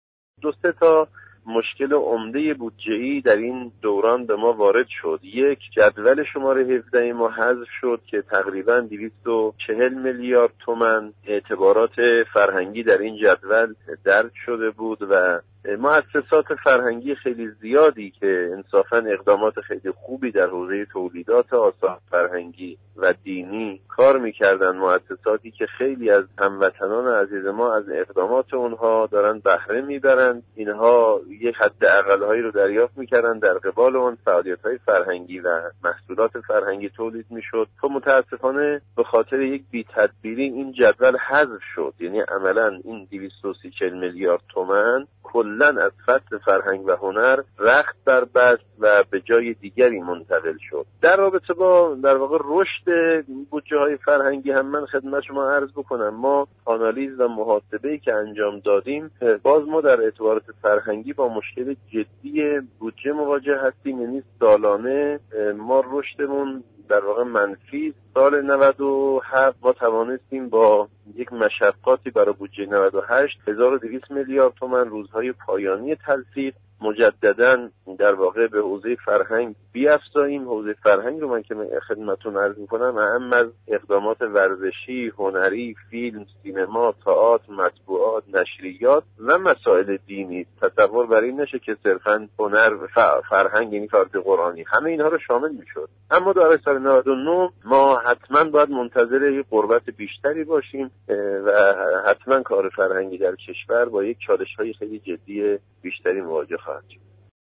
حجت‌الاسلام آزادیخواه در گفتگو با خبر رادیومعارف گفت متأسفانه درصد تخصیص‌ها نیز بسیار پایین است و اولویت‌ها در نظر گرفته نشده است.